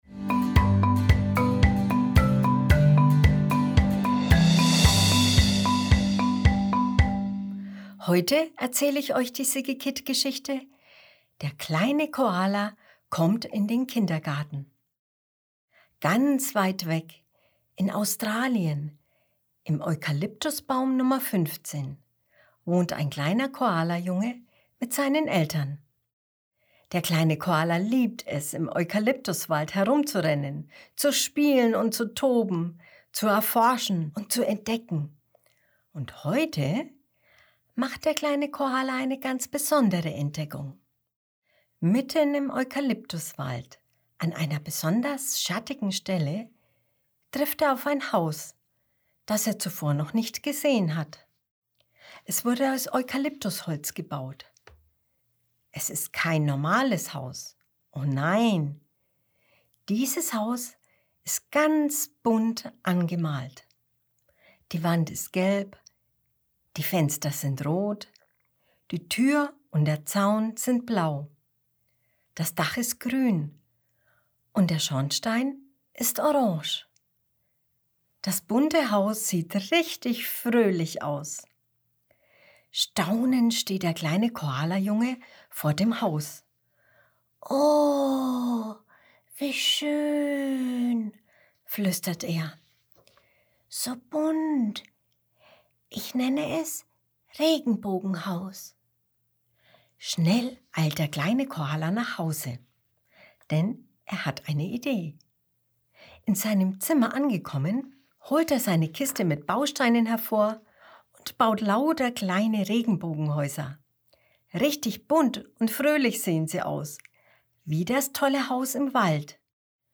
Juli 2021 Kinderblog Kindergarten & Vorschule, Vorlesegeschichten In Australien, im Eukalyptusbaum mit der Baumnummer 15, wohnt ein kleiner Koalajunge mit seinen Eltern.